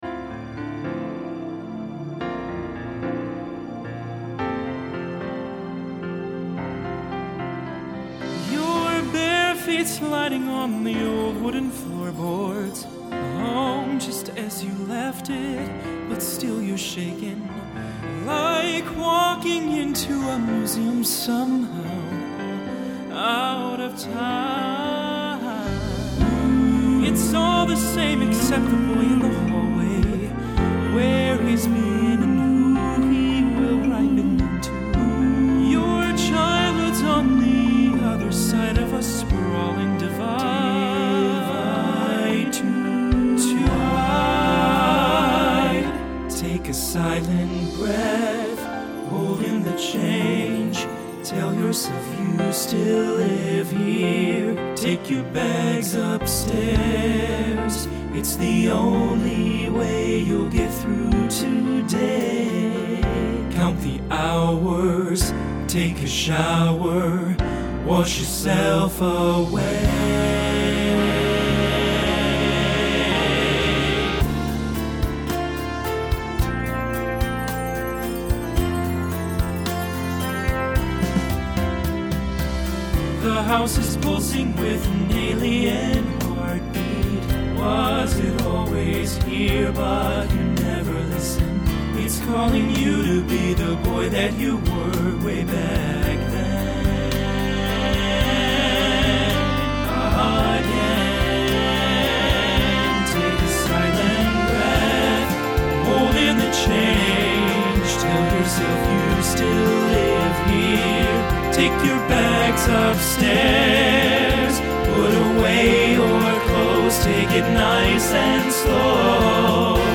Genre Broadway/Film Instrumental combo
Function Ballad Voicing TTB